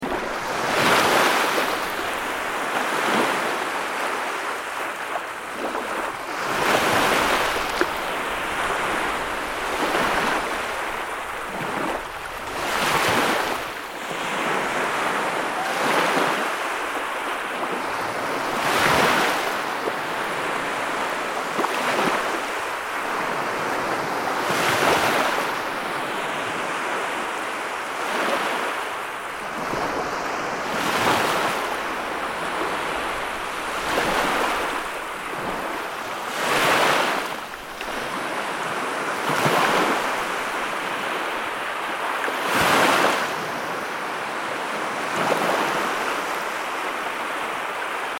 دانلود آهنگ موج 12 از افکت صوتی طبیعت و محیط
دانلود صدای موج 12 از ساعد نیوز با لینک مستقیم و کیفیت بالا
جلوه های صوتی